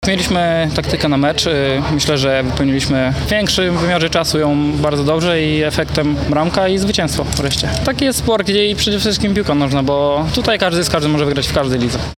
który w pomeczowej rozmowie nie krył zadowolenia z wyniku.